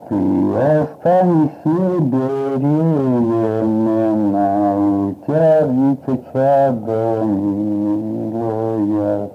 Произнесение слова тебя как тя
/ты-а-ста”-н’е-шс’е б’е-р’еее”-м’е-на у-т’а-бу”-д’ет ча”-до м’ии”-